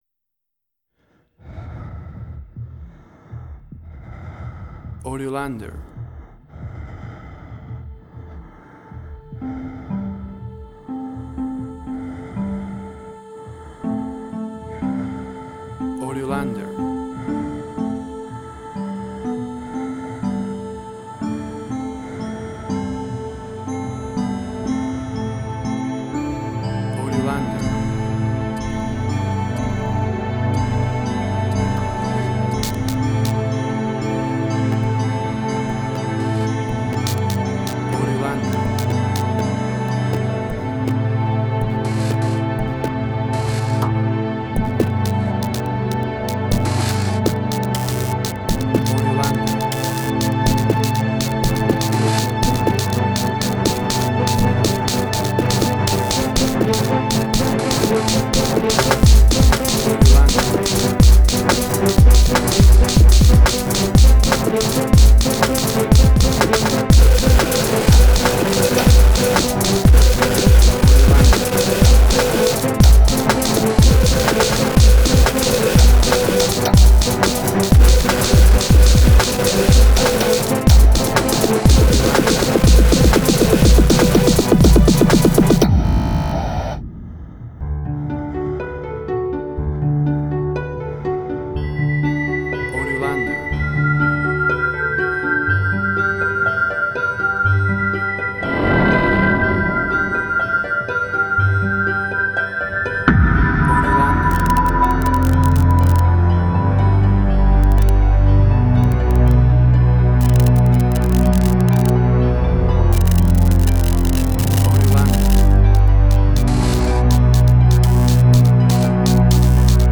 IDM, Glitch.